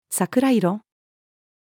桜色-cherry-blossom-(colour-female.mp3